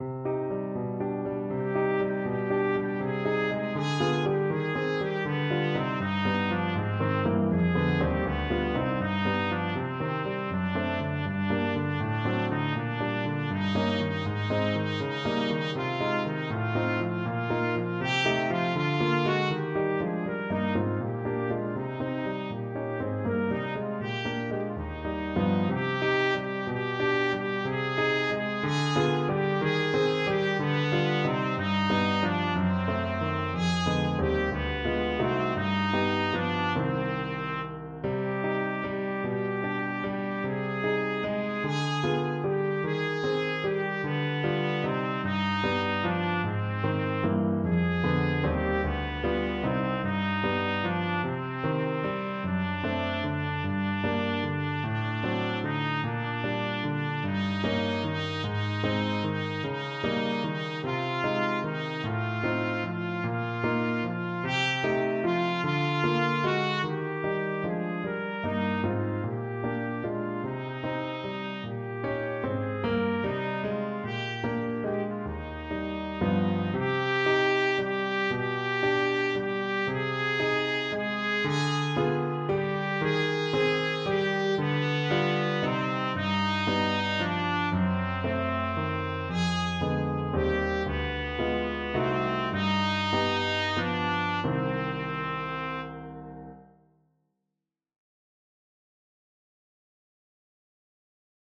Trumpet version
6/8 (View more 6/8 Music)
Trumpet  (View more Easy Trumpet Music)
Classical (View more Classical Trumpet Music)